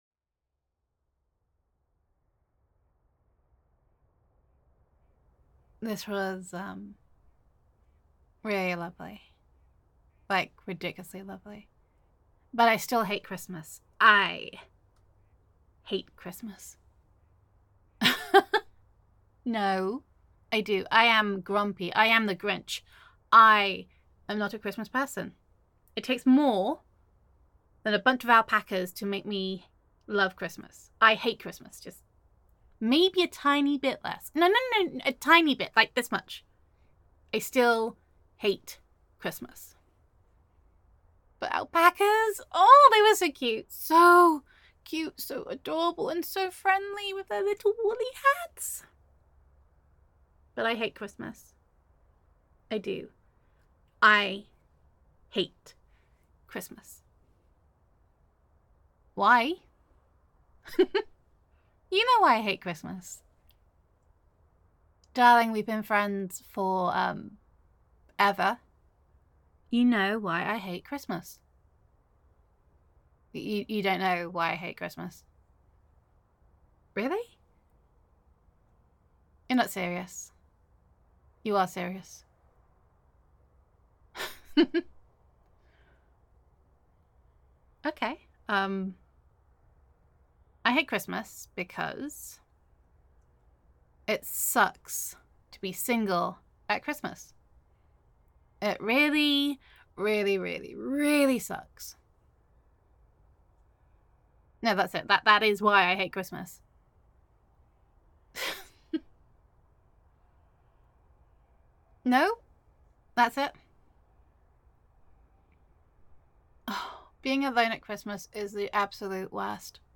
[F4A] I Hate Christmas
[Best Friend Roleplay]